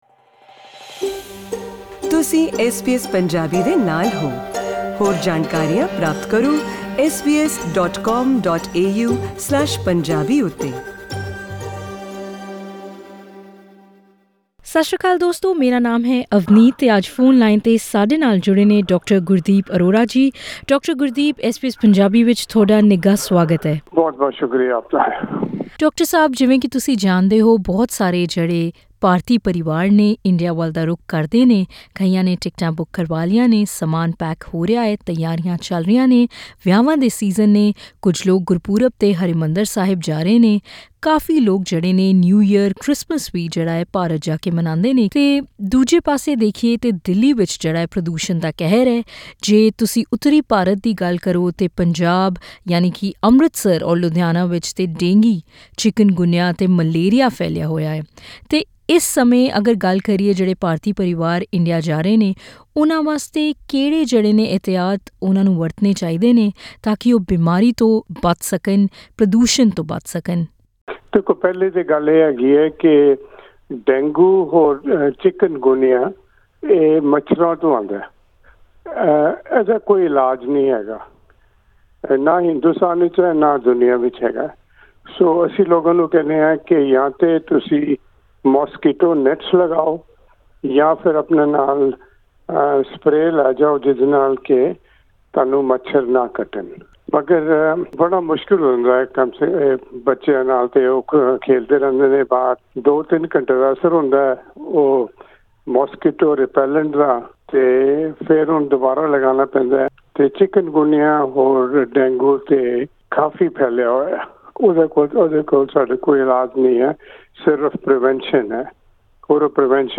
Click on the player to listen to the full interview with Melbourne based